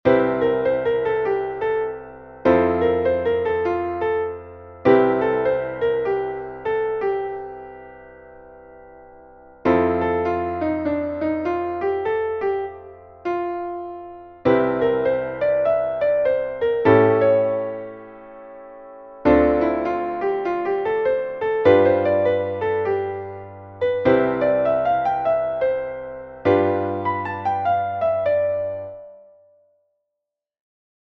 Ich habe die Akkorde ein wenig geändert, um ihn fröhlicher zu machen. Die I- und IV-Akkorde sind Dur. Die Blue Notes erscheinen nur auf dem Offbeat. Es gibt keinen Tritonus.
Hier ist er also als Computerversion von MuseScore.